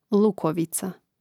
lȕkovica lukovica